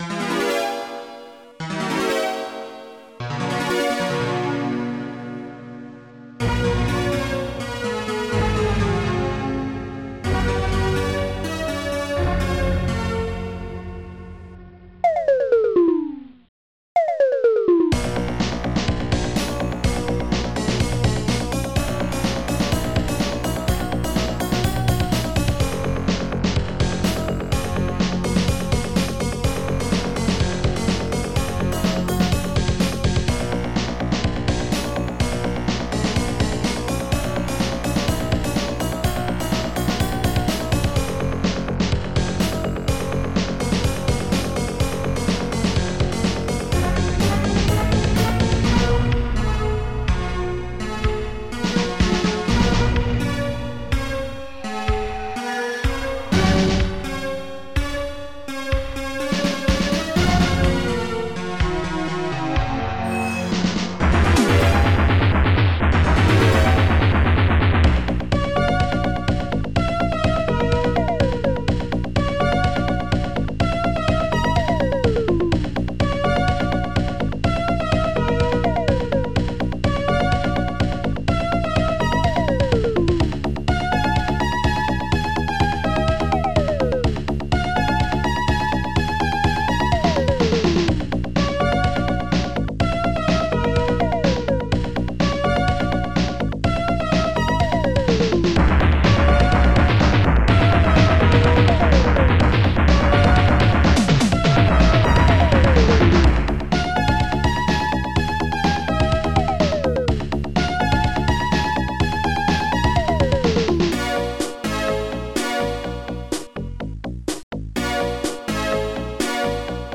st-67:d-50harpsi
st-89:snare2
st-89:bassdrum11
st-68:electroguitar